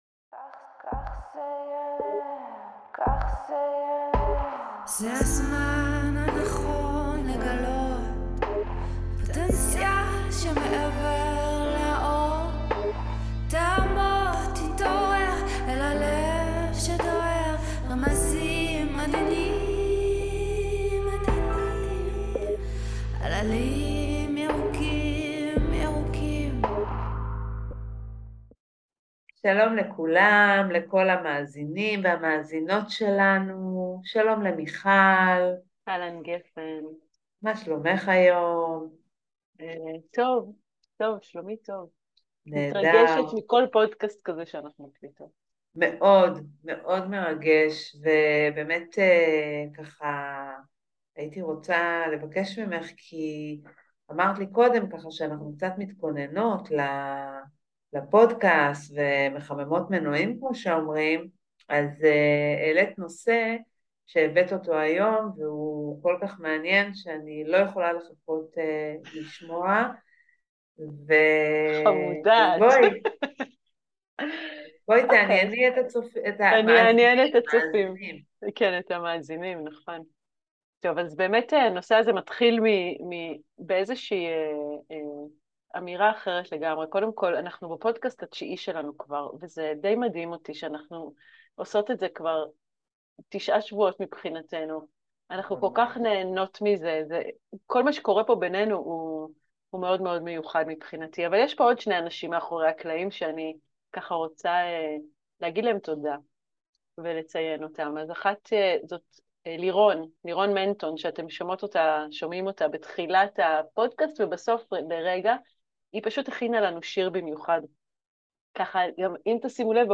שיחה על הכנות ותכניות בחיים, איך אנחנו מכינים את עצמנו לקראת...